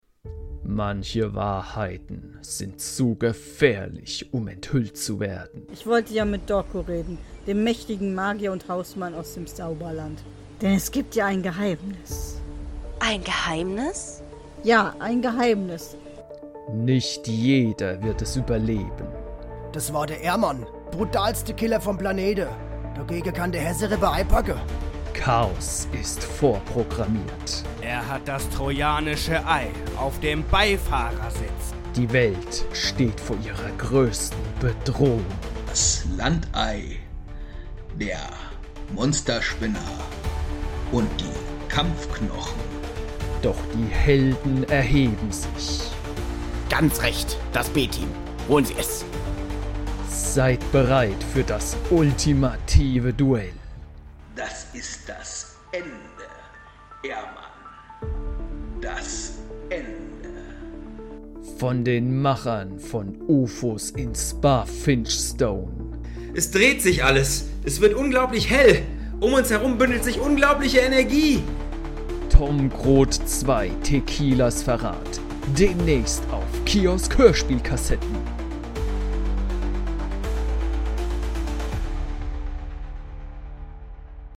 Tequilas Verrat (Tom Grot II) - Teaser Trailer zum zweiten Fanhörspiel (#57)